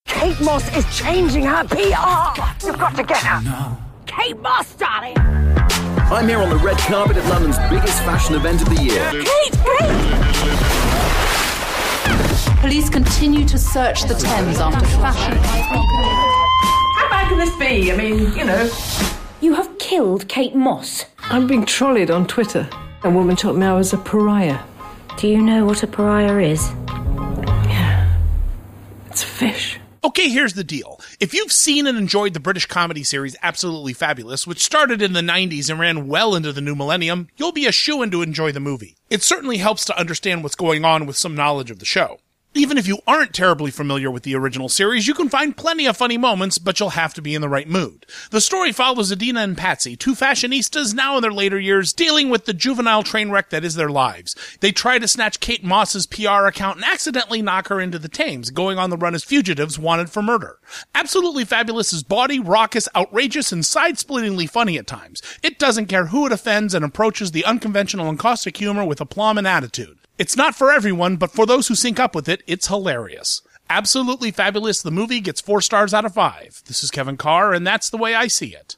‘Absolutely Fabulous’ Radio Review